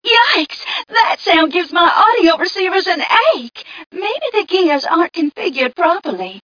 1 channel
mission_voice_t9ca014.mp3